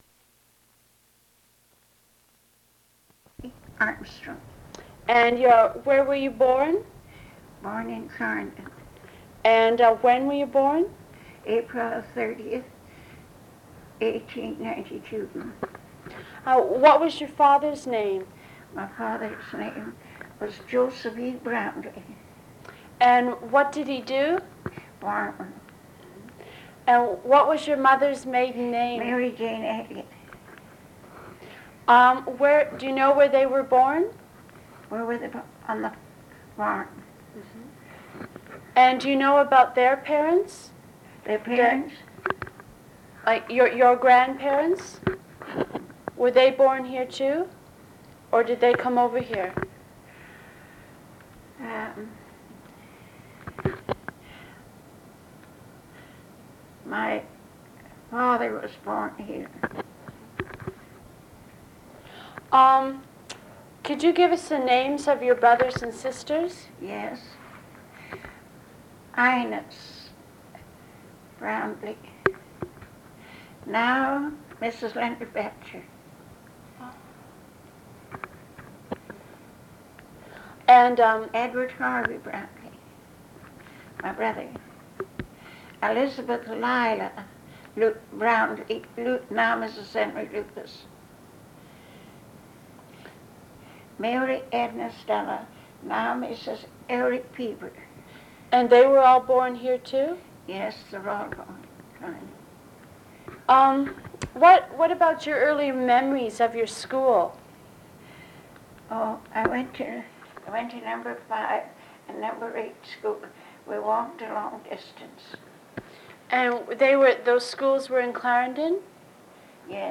Première personne interrogée